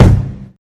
KICKMURDA.wav